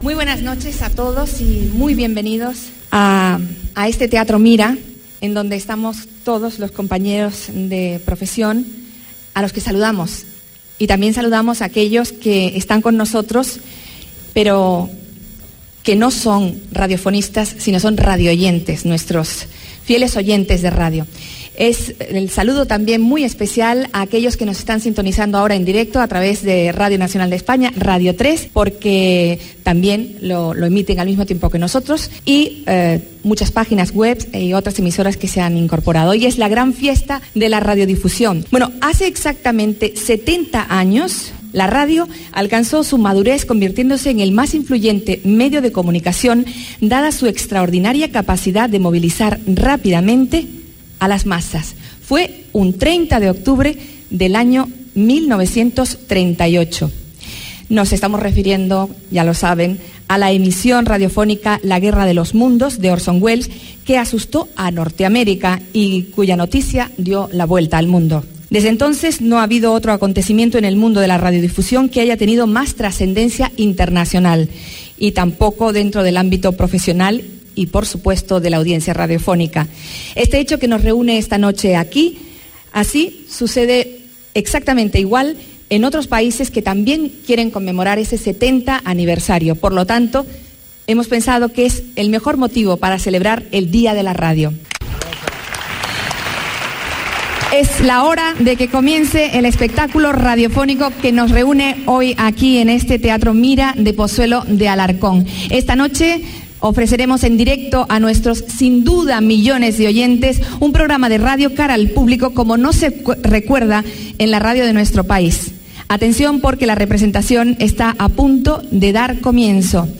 Introducció i primers minuts de l'emissió de "La guerra de los mundos", feta en directe des del Teatro Mira de Pozuelo de Alarcón, amb motiu del 70è aniversari de la seva primera emissió a la Columbia Broadcasting System dels EE.UU.
Ficció